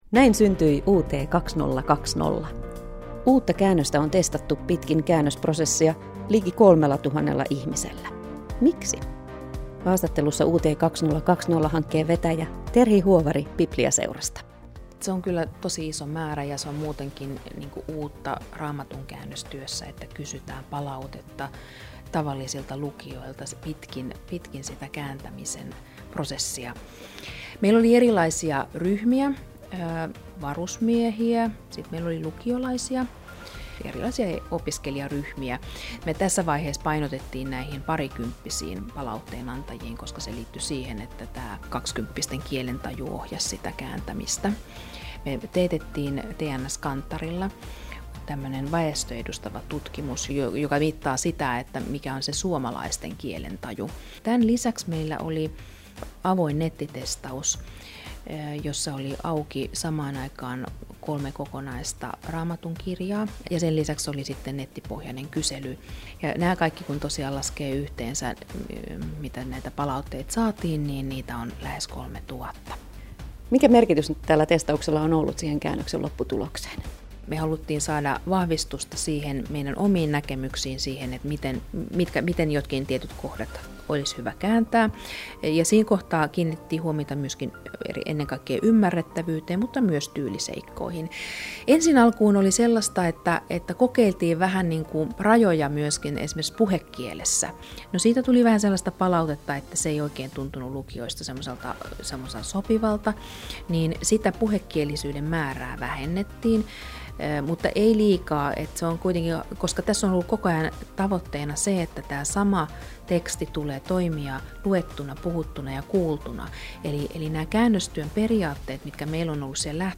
Haastattelussa